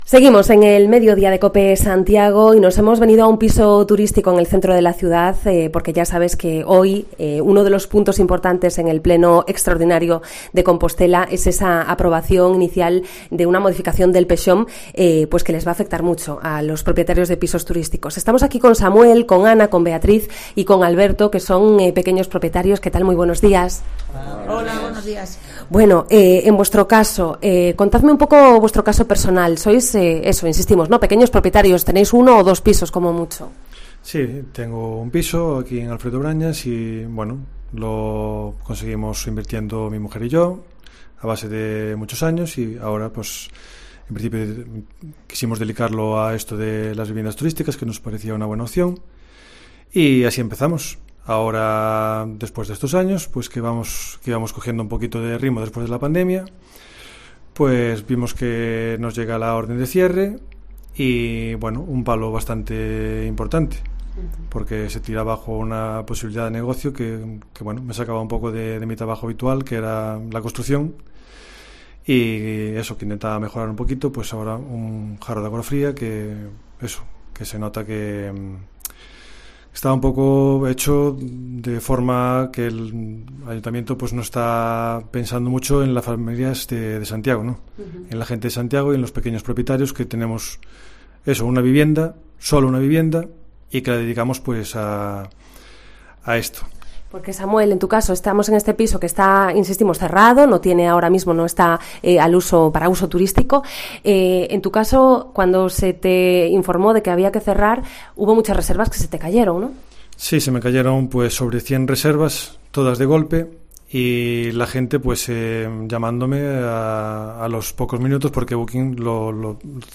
Emitimos el Mediodía de COPE Santiago desde un piso turístico de Alfredo Brañas.